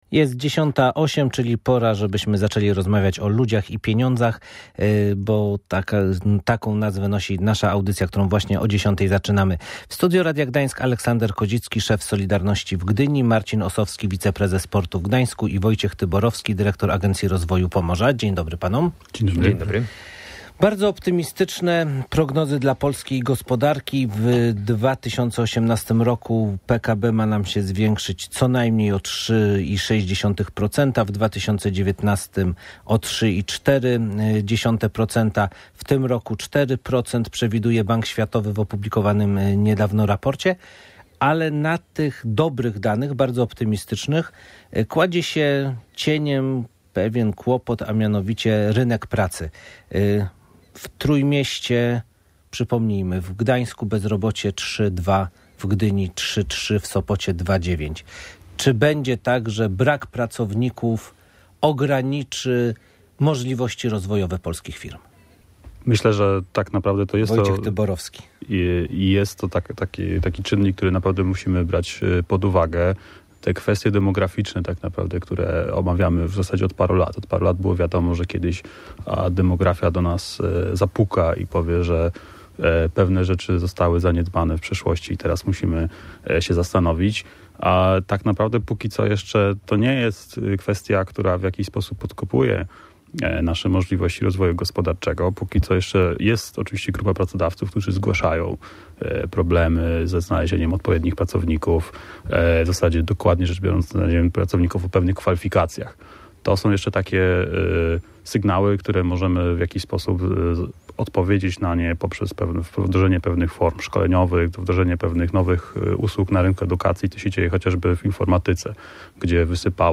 Czy brak pracowników może ograniczyć możliwości polskich firm? O tym dyskutowali goście audycje Ludzie i Pieniądze. Poruszana była także kwestia PKB.